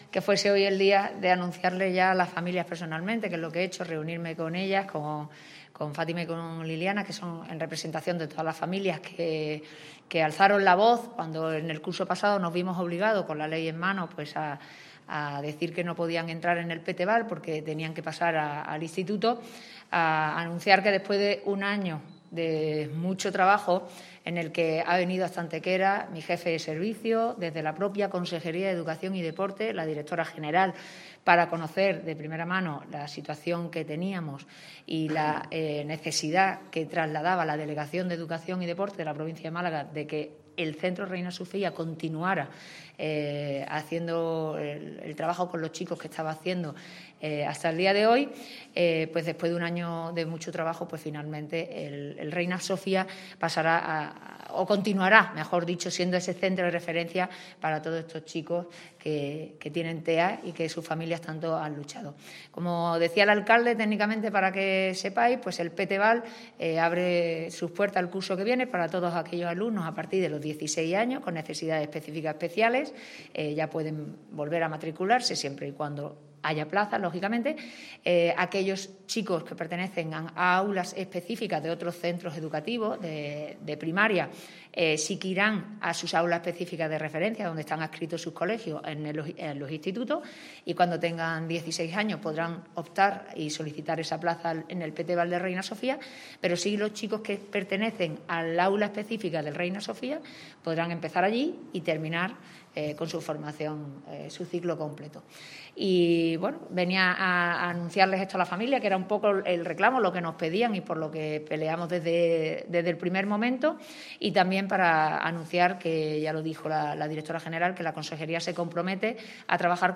El alcalde de Antequera, Manolo Barón, y la delegada territorial de la Consejería de Educación en Málaga, Mercedes García Paine, han comparecido en el mediodía de hoy ante los medios de comunicación para informar de positivas novedades respecto a las peticiones de las familias de unos 17 alumnos con necesidades educativas especiales actualmente matriculados en el CEIP Reina Sofía que, gracias a las gestiones realizadas, podrán continuar su formación específica hasta los 21 años en el propio Reina Sofía.
Cortes de voz